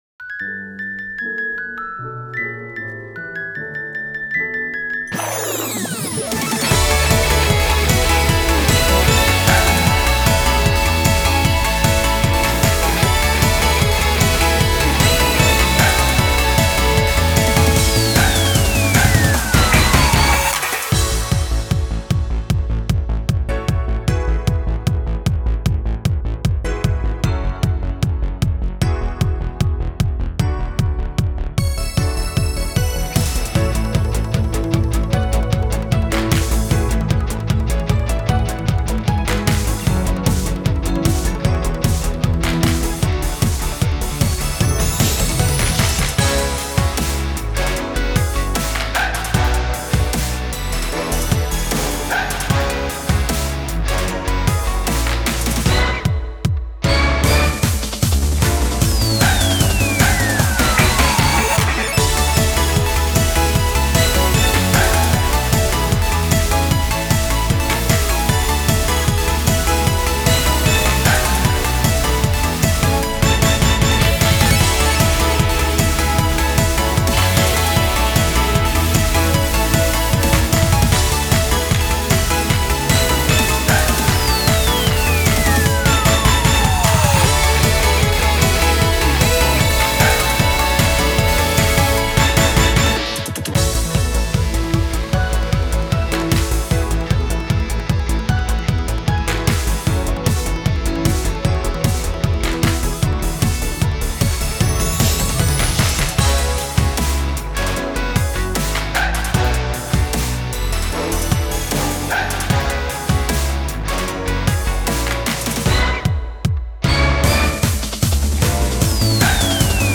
カラオケ （メロディあり）